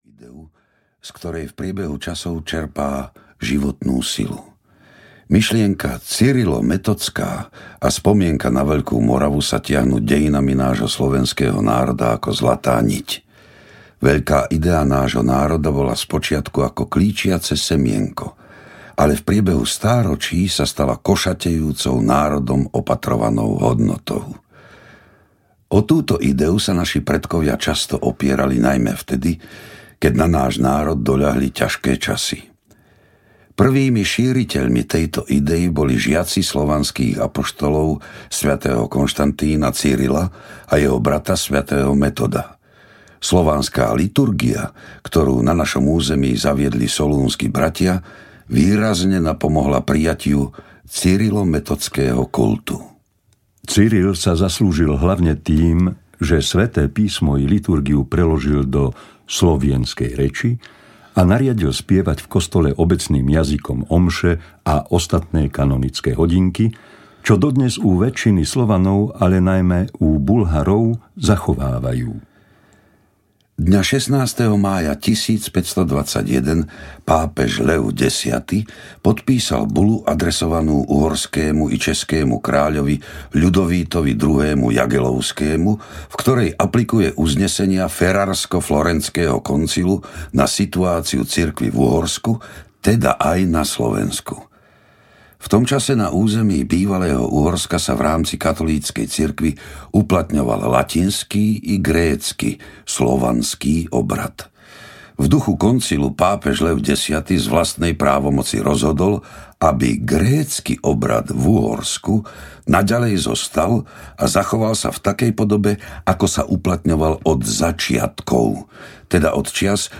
Zo života solúnskych bratov audiokniha
Ukázka z knihy